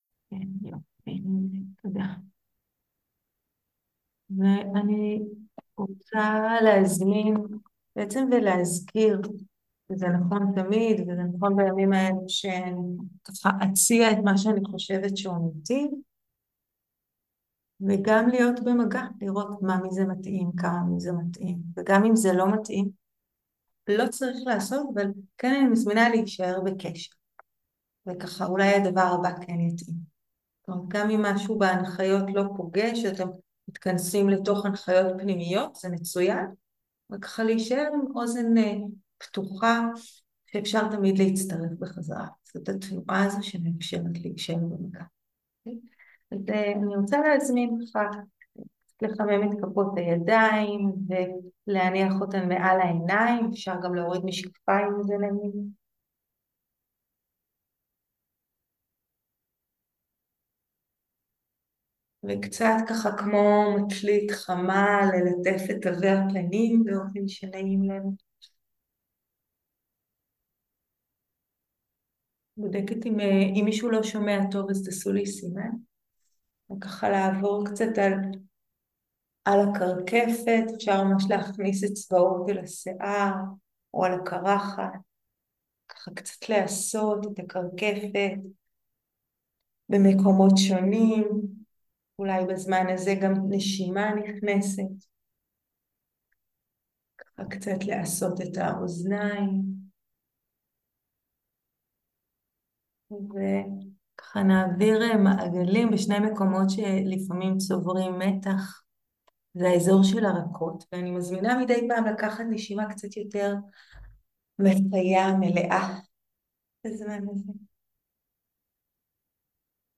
01.11.2023 - מרחב בטוח - תרגול עם דימויים של נהר והר + תרגול של לחלוק את הטוב
מרחב בטוח בזום דאנה למורה